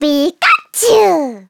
Cri de Pikachu dans Pokémon Soleil et Lune.